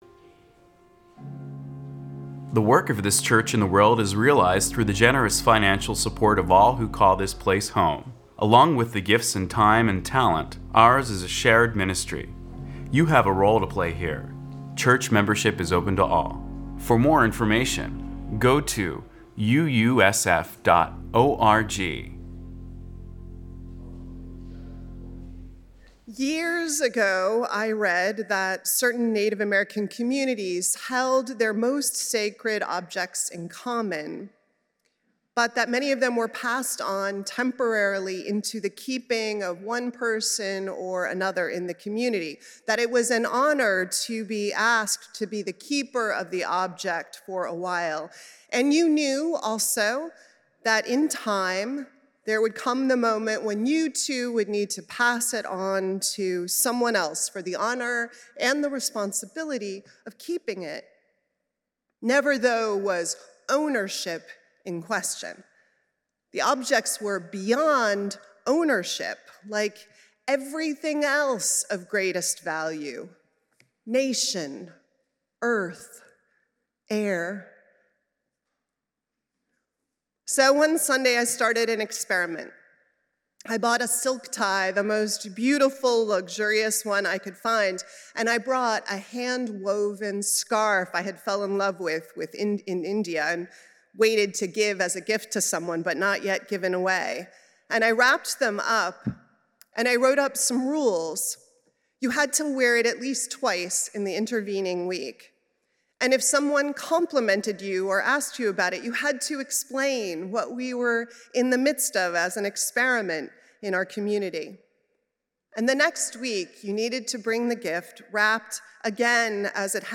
First Unitarian Universalist Society of San Francisco Sunday worship service.